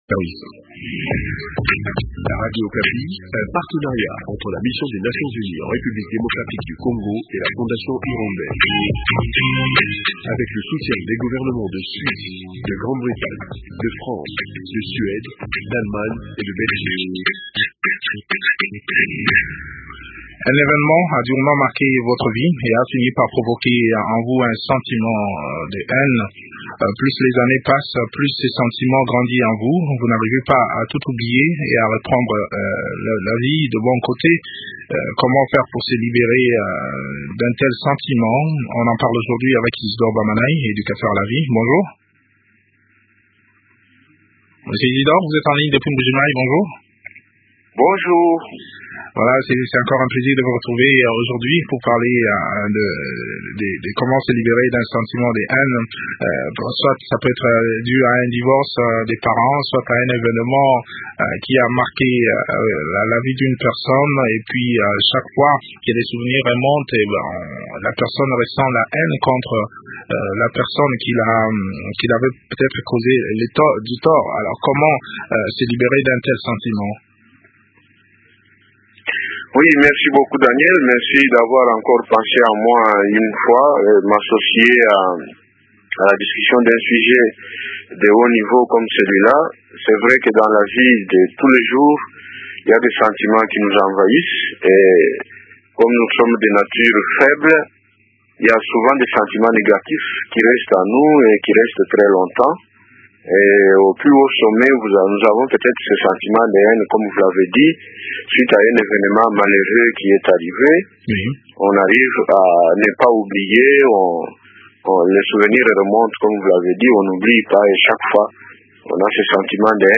Comment faire pour se libérer moralement? Eléments de réponse dans cet entretien